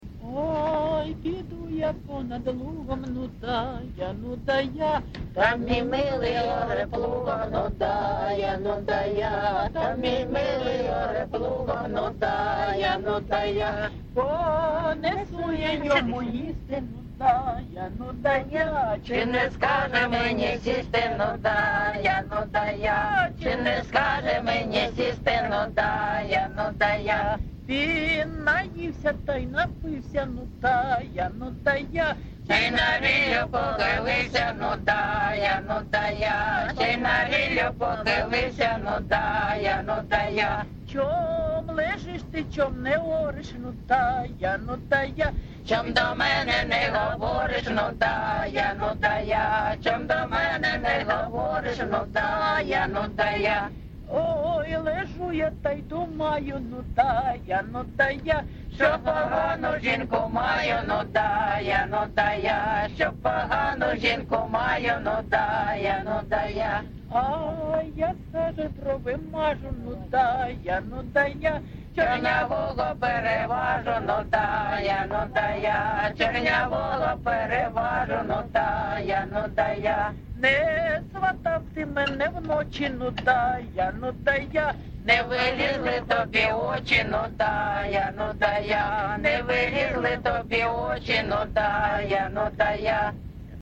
ЖанрЖартівливі
Місце записус. Богородичне, Словʼянський район, Донецька обл., Україна, Слобожанщина